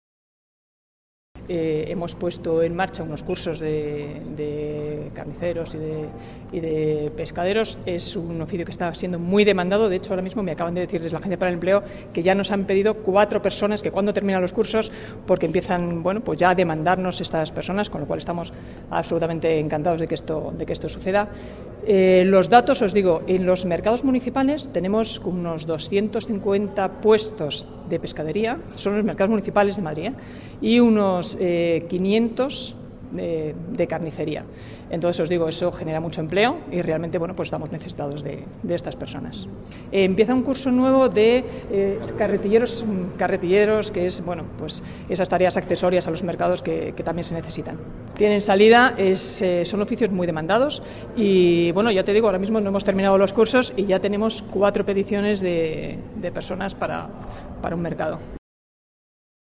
Marta Higueras visita el Centro de Formación de Mercamadrid en el que se imparten cursos de carnicería y pescadería
Nueva ventana:Palabras de Marta Higueras sobre los cursos formativos